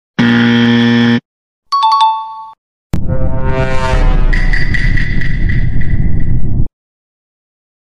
Wrong Right & Sus sound sound effects free download